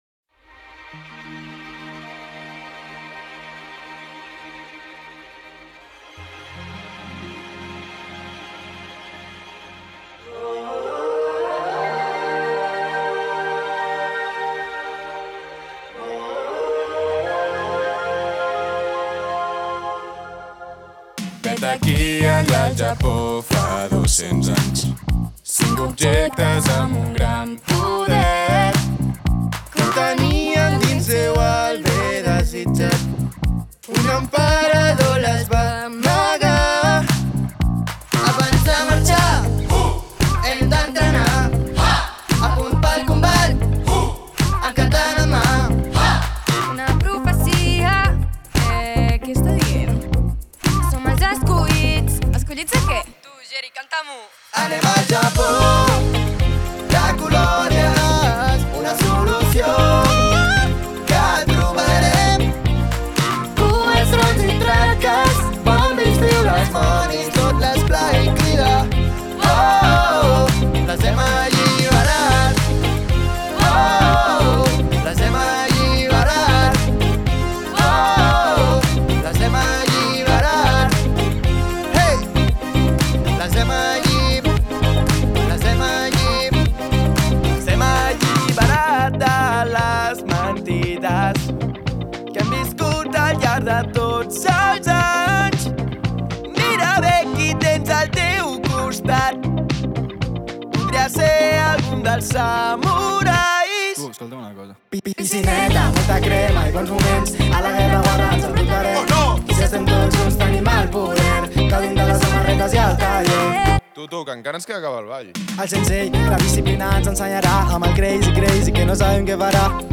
El Ginebró 2024